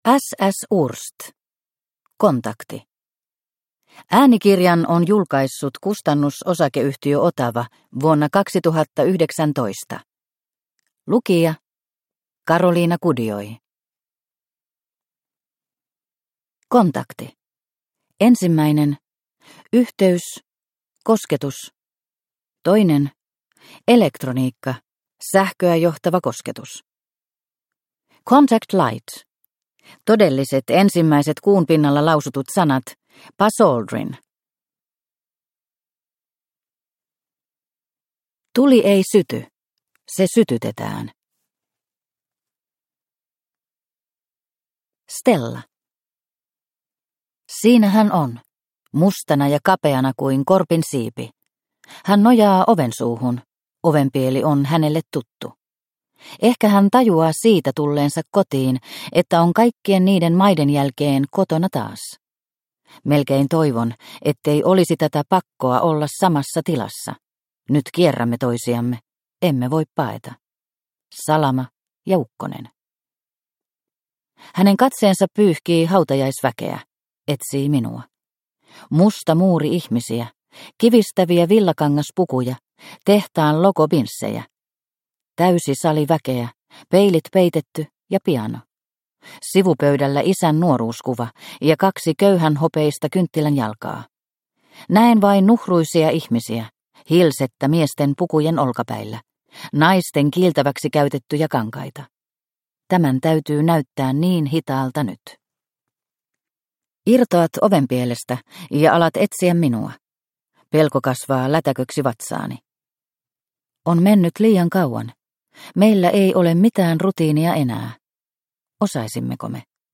Kontakti – Ljudbok – Laddas ner